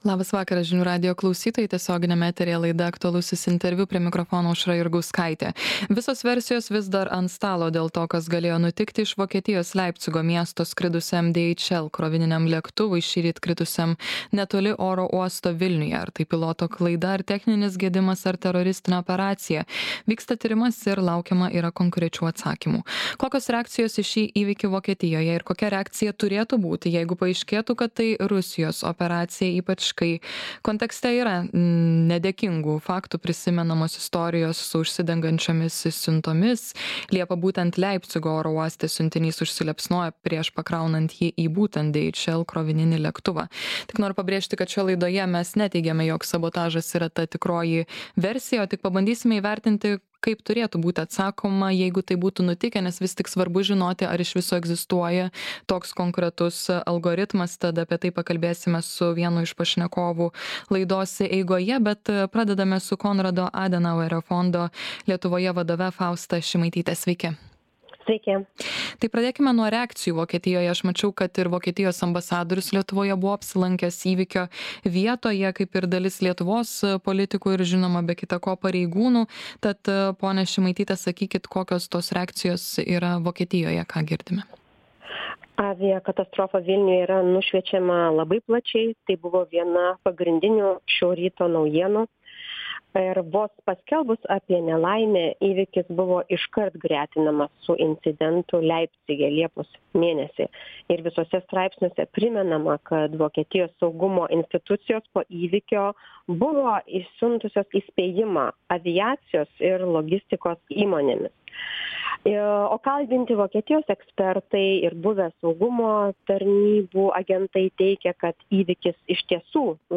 Aktualusis interviu